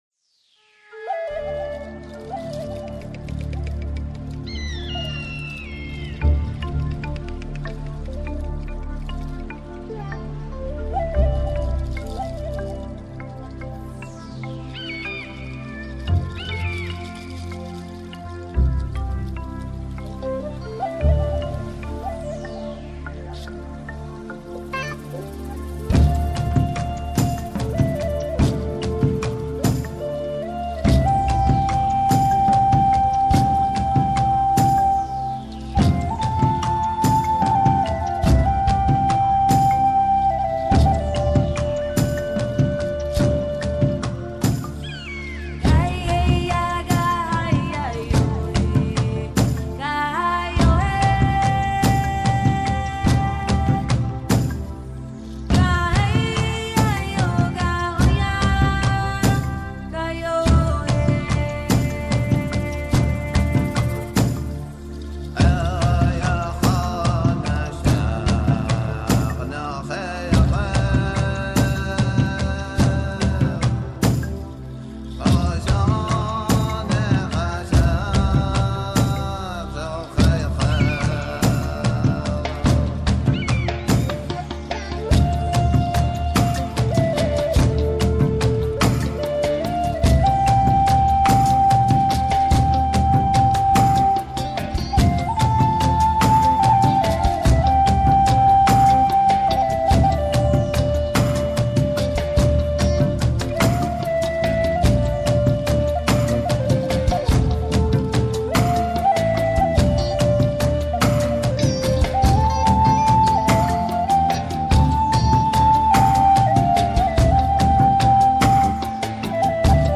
Инструментальная музыка New Age Ambient